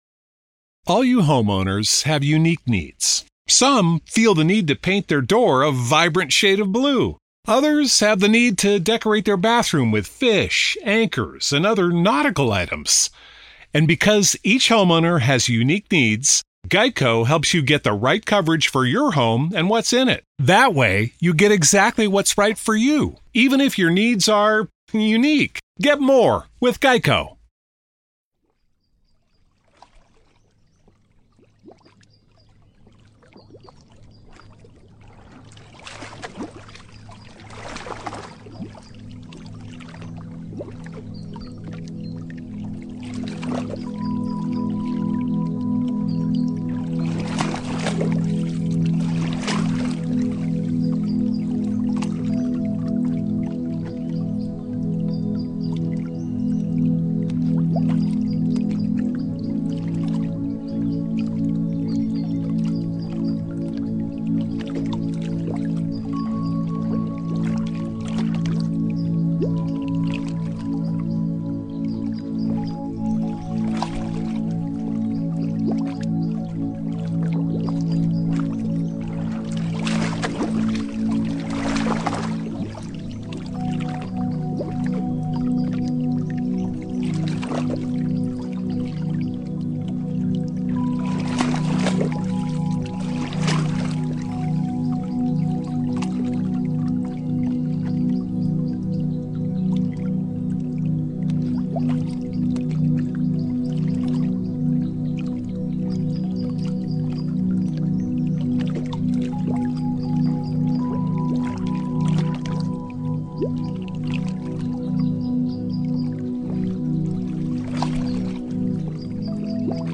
Hypnosis and relaxation ｜Sound therapy - Wetland Rowing Boat Soothes you into deep sleep Fall asleep quickly Improves sleep quality